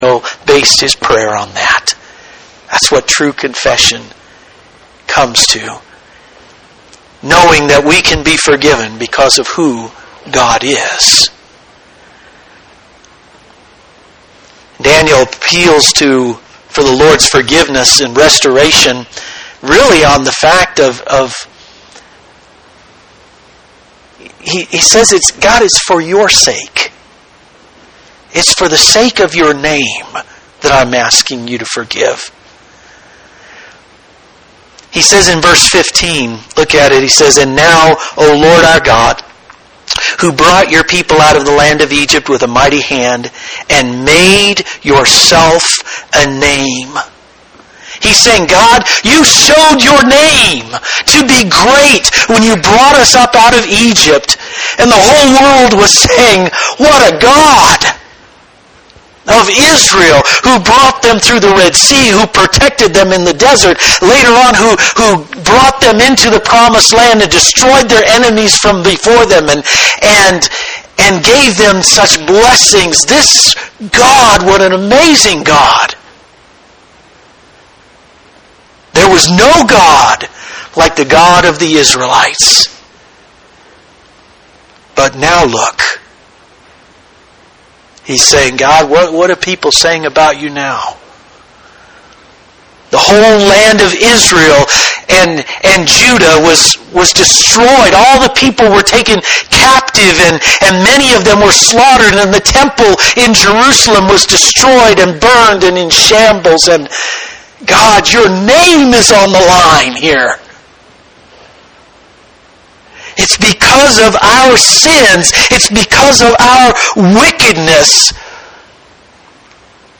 (Please note that this sermon recording only includes the end of the sermon because of a technical problem we had while recording it. Sorry we did not get the whole thing recorded)